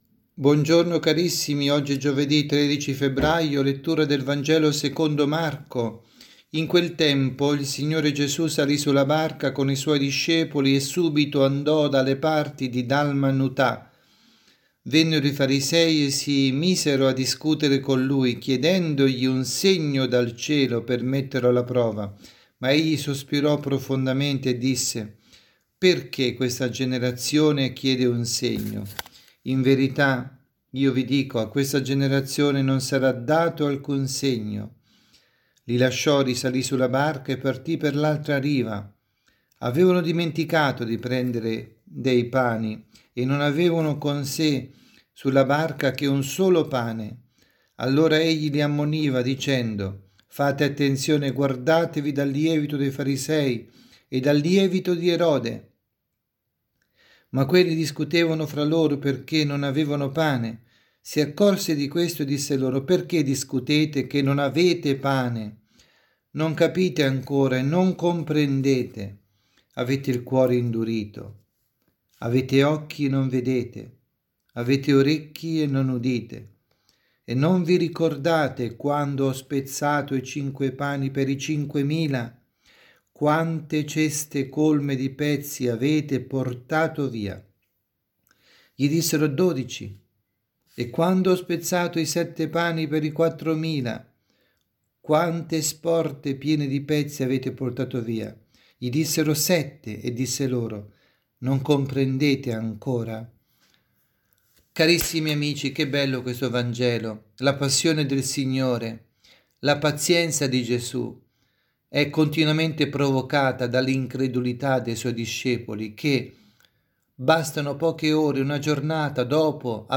avvisi, Omelie